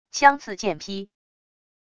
枪刺剑劈wav音频